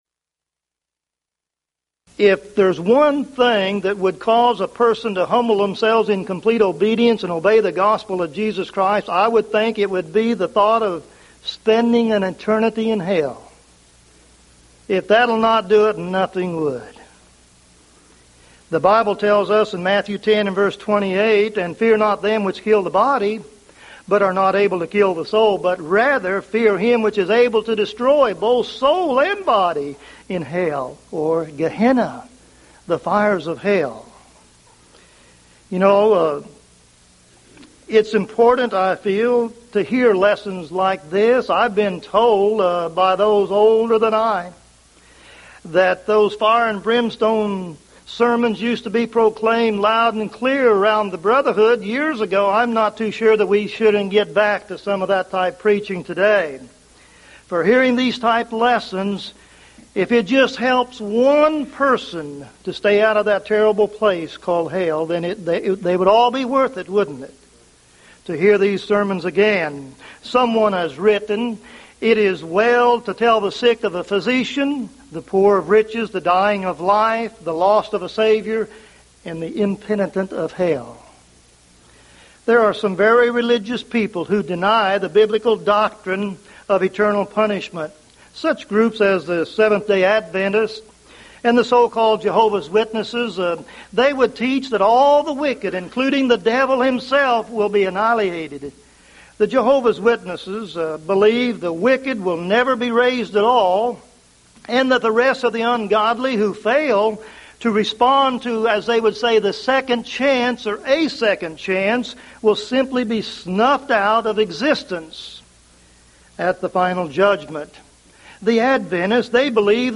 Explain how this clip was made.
Event: 1998 Mid-West Lectures